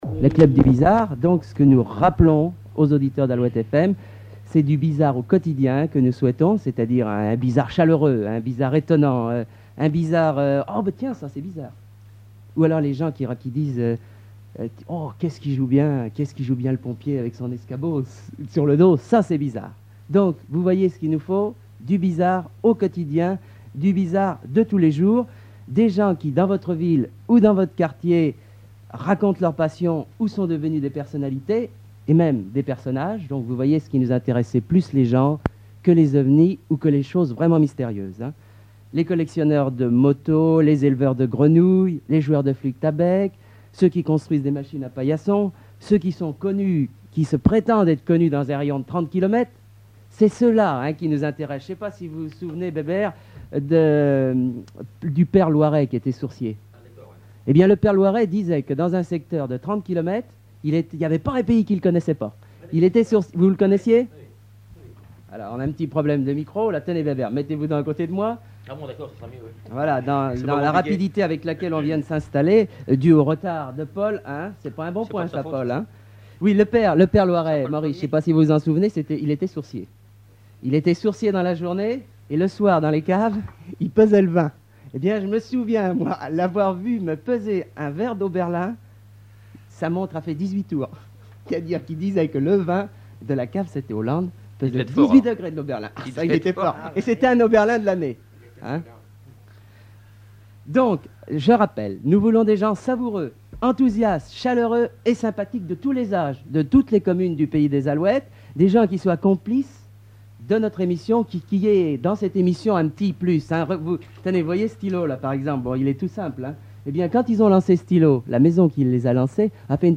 Définition de l'émission Bizarre Détails de l'archive Titre Définition de l'émission Bizarre Note émissions d'Alouette FM Mots Clé média (presse, radio, télévision...)
Catégorie Témoignage